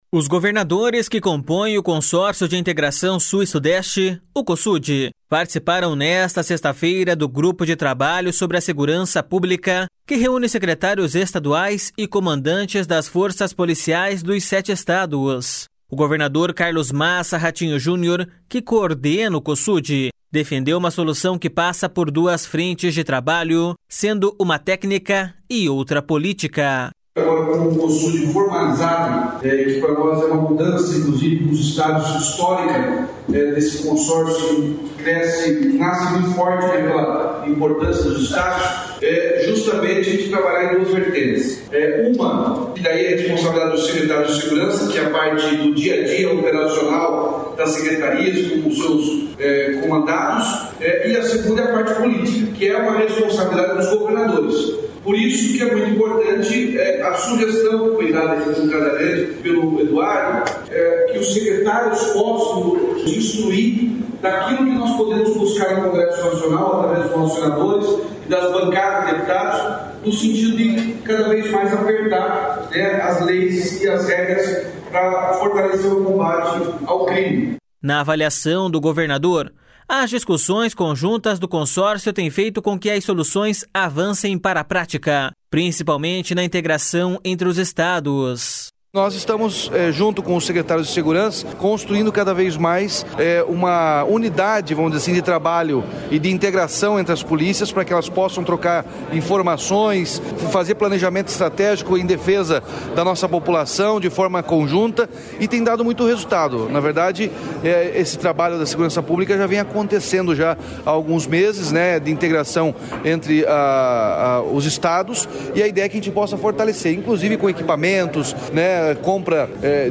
Os governadores que compõem o Consórcio de Integração Sul e Sudeste, Cosud, participaram nesta sexta-feira do Grupo de Trabalho sobre segurança pública, que reúne secretários estaduais e comandantes das forças policiais dos sete estados. O governador Carlos Massa Ratinho Junior, que coordena o Cosud, defendeu uma solução que passa por duas frentes de trabalho, sendo uma técnica e outra política.// SONORA RATINHO JUNIOR.//
Presente desde o início das discussões do Grupo de Trabalho, o secretário da Segurança Pública do Paraná, Hudson Teixeira, citou alguns pontos que já são consensuais entre os estados e que devem ser encaminhados a partir da conclusão do Cosud.// SONORA HUDSON TEIXEIRA.//
O governador de São Paulo, Tarcísio de Freitas, ressaltou que as soluções debatidas deverão constar na Carta de São Paulo, documento em que devem constar as conclusões do 9º Cosud e que será publicada neste sábado.// SONORA TARCÍSIO DE FREITAS.//